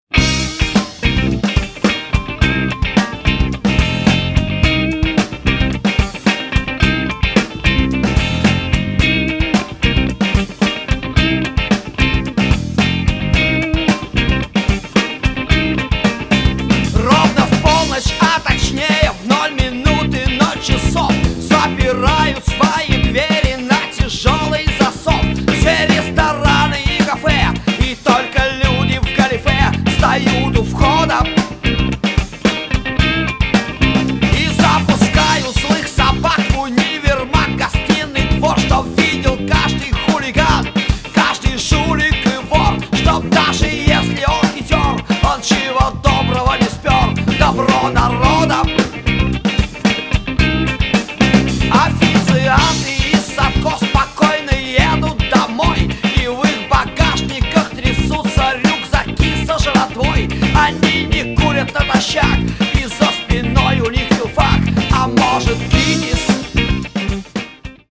здорово и жестко отпел